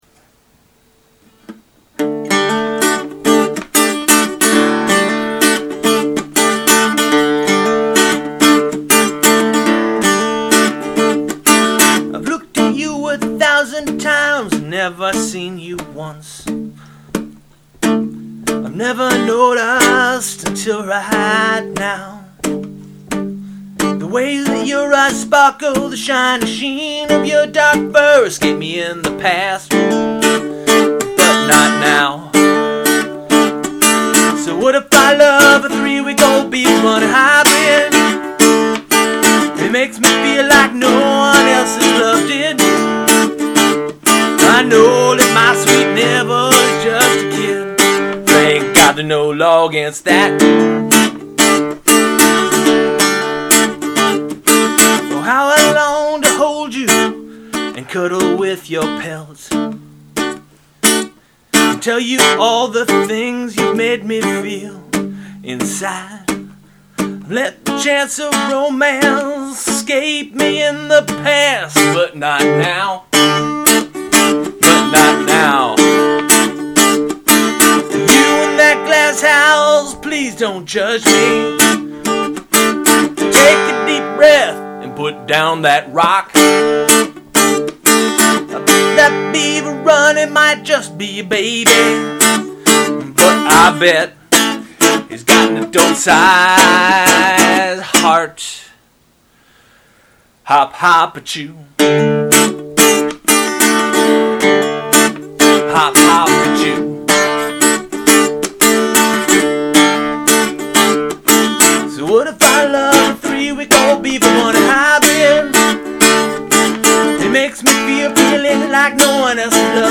demo track of the above song